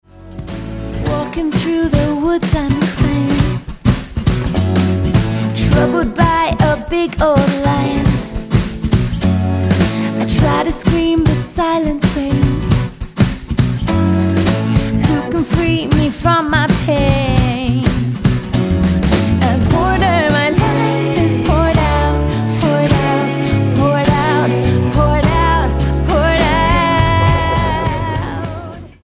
Australian singer
Style: Pop